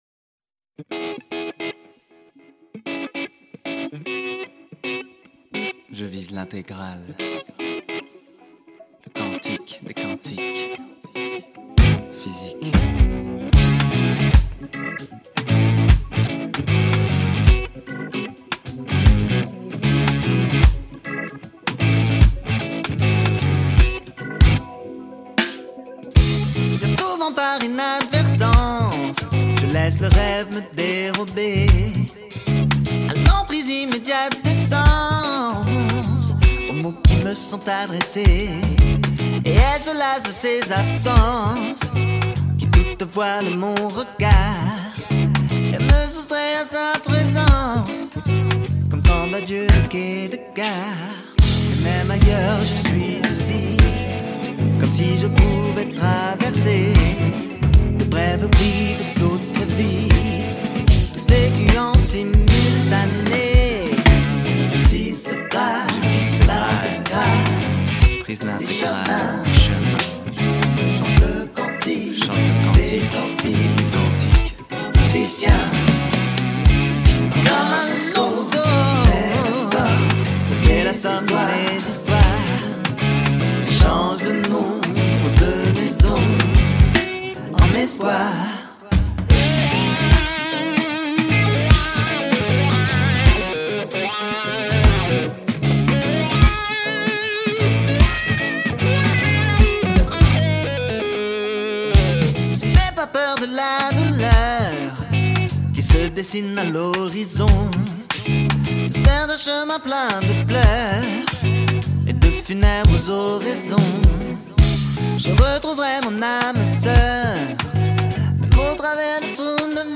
guitariste